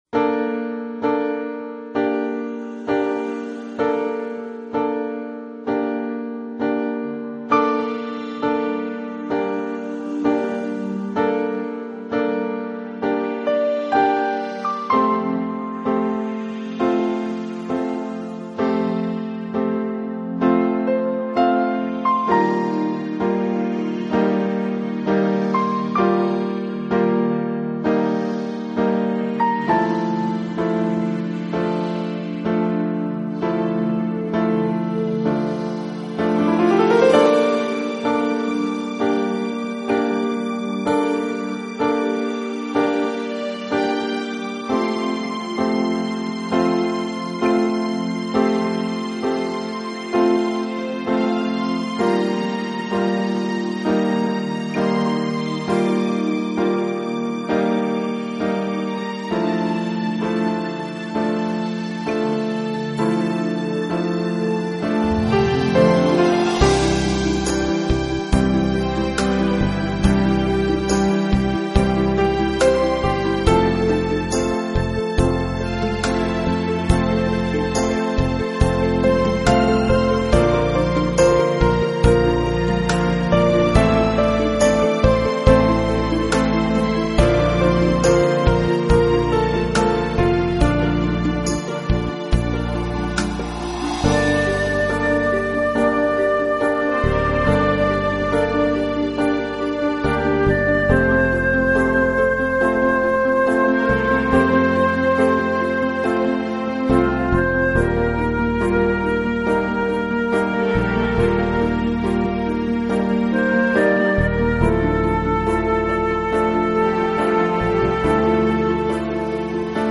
【钢琴纯乐】
专辑语言：纯音乐
醉在爱情音乐世界里浪漫的新世纪钢琴演奏专辑！